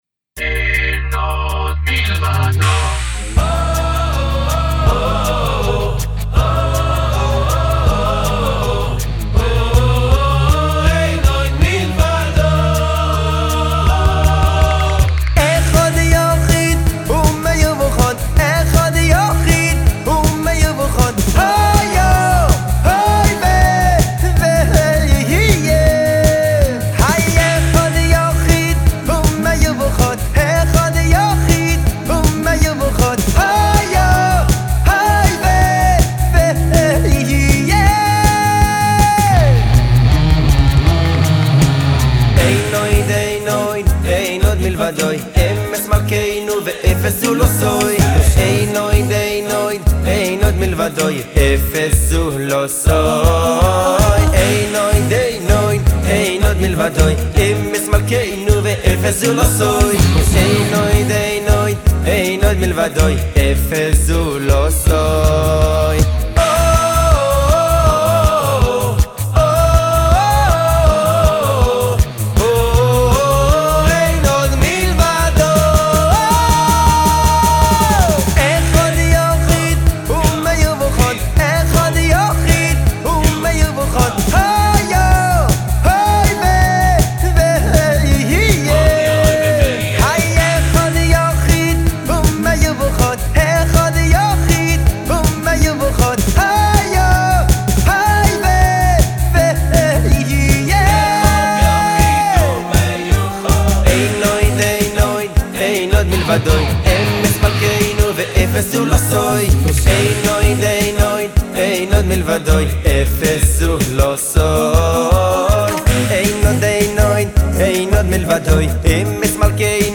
שיר קצבי ותוסס שכבש בין רגע את מצעדי הפזמונים ותחנות הרדיו.